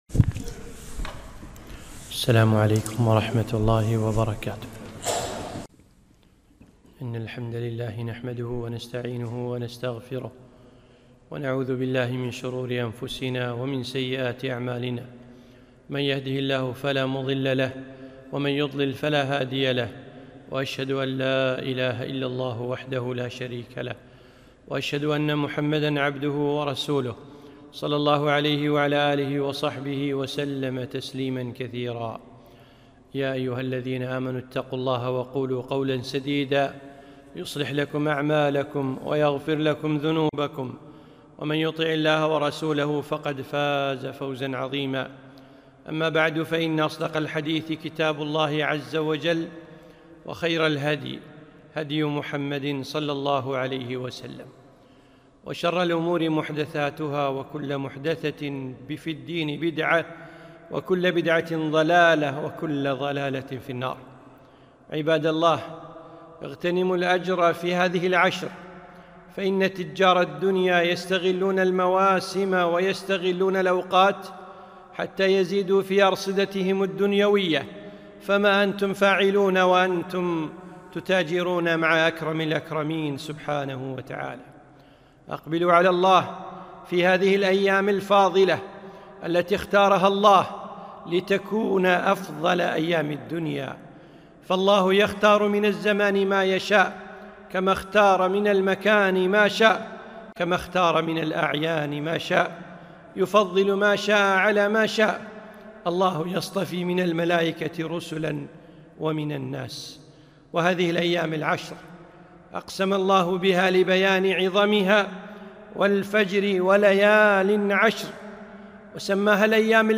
خطبة - اغتنموا الأجر في العشر